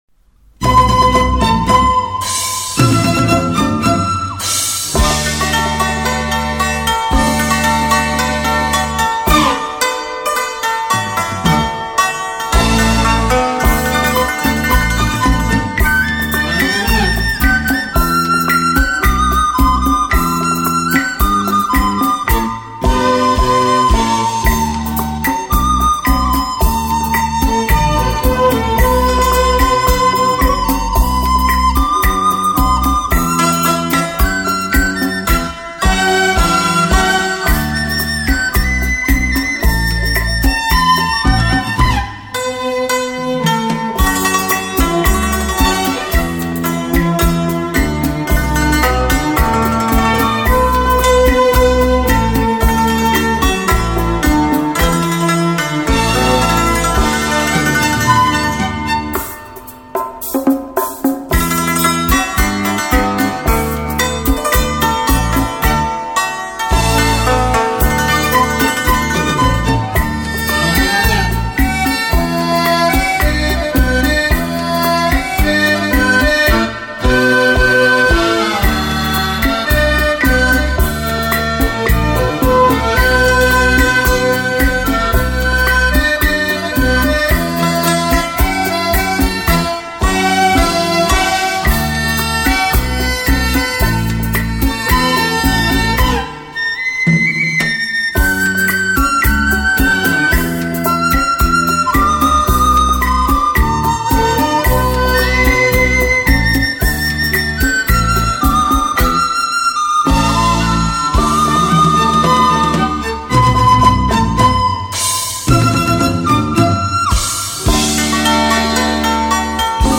专辑格式：DTS-CD-5.1声道
閒適優雅的音符，完美傳真的音質，呈獻在您的耳際
優美動聽的舞蹈旋律讓人沉醉其中...